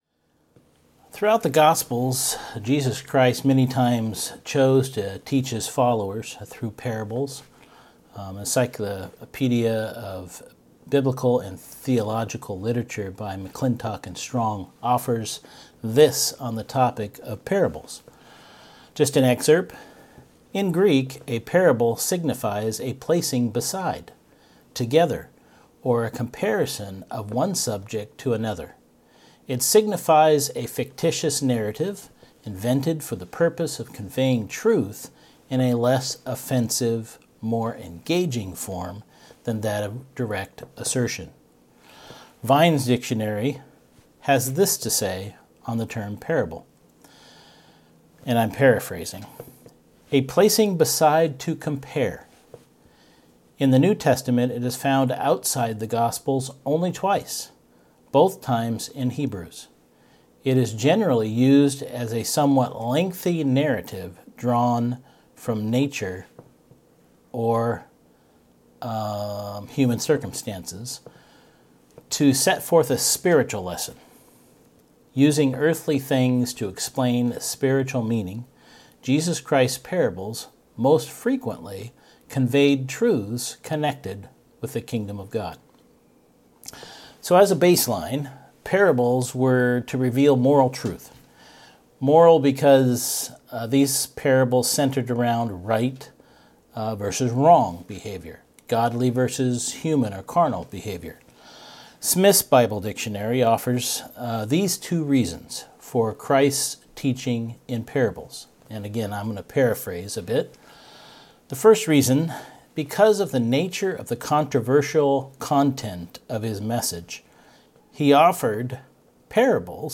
Given in Seattle, WA